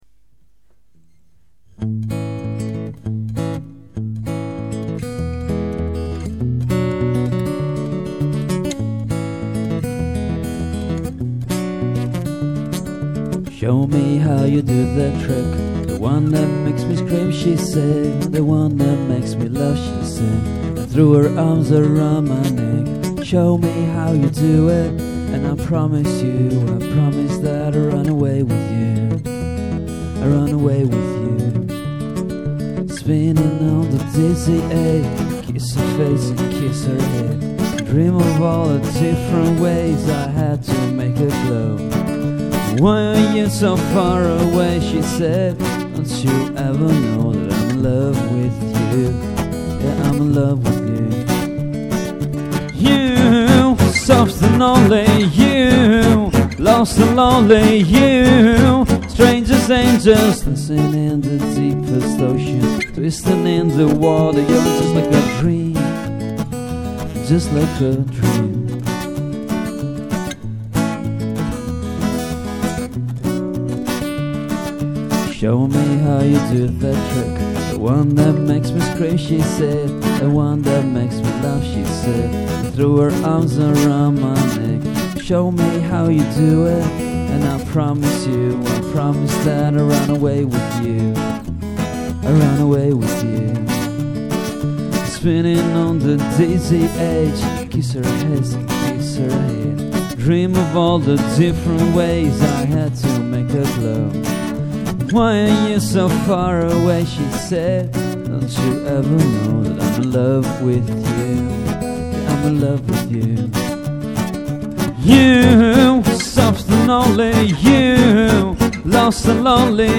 Finger picking. Low profile. Funny.